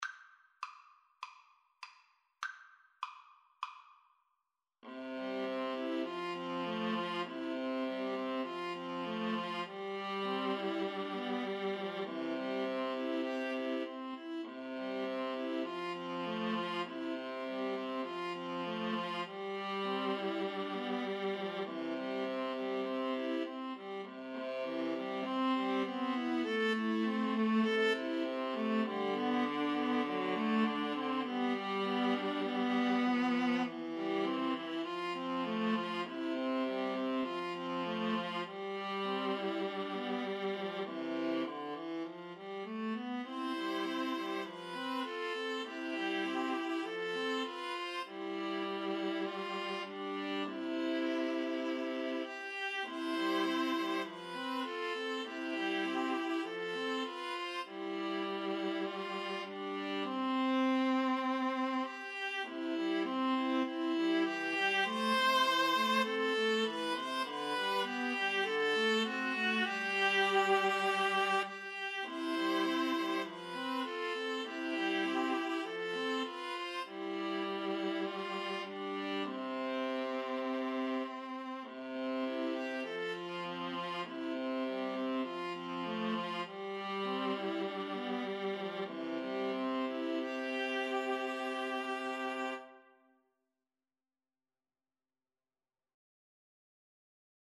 Free Sheet music for Viola Trio
C major (Sounding Pitch) (View more C major Music for Viola Trio )
4/4 (View more 4/4 Music)
Andante
Viola Trio  (View more Intermediate Viola Trio Music)
Traditional (View more Traditional Viola Trio Music)
Scottish